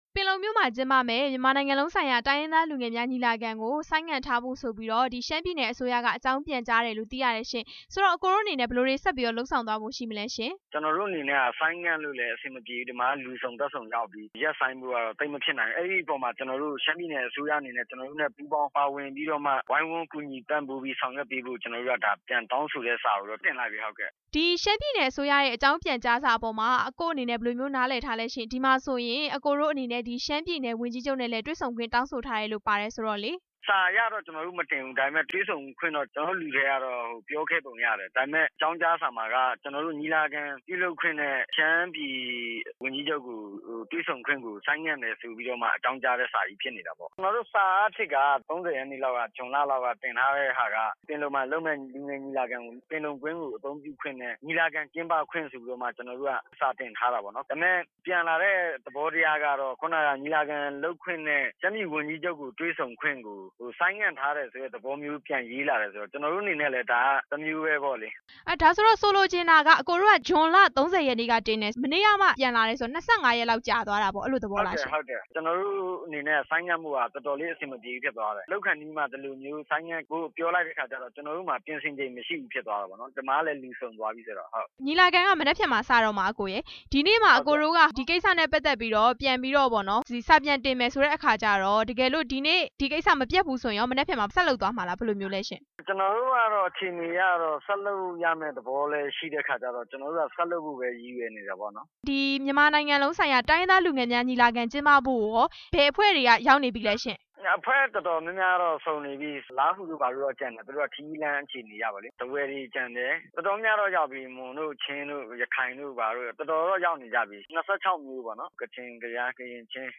တိုင်းရင်းသားလူငယ်များညီလာခံ ကျင်းပခွင့်ရဖို့ ကြိုးစားနေတဲ့အကြောင်း မေးမြန်းချက်